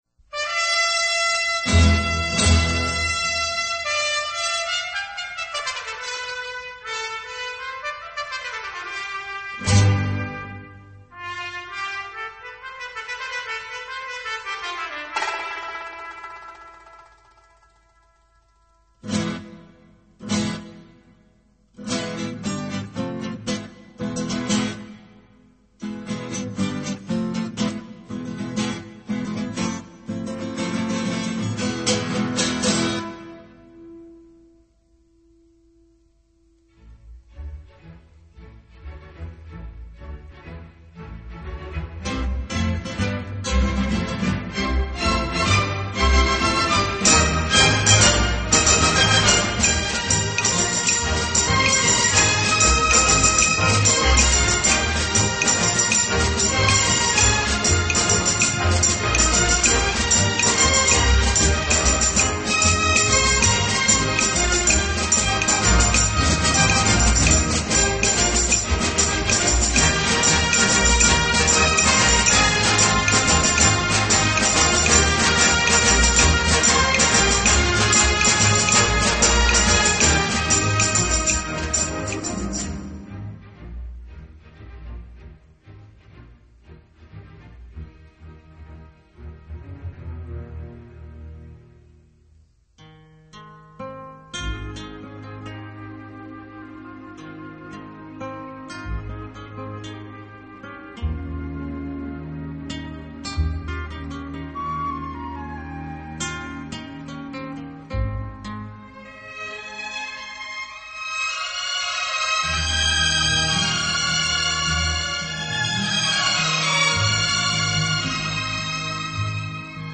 这首气势磅礴的经典旋律你一定熟悉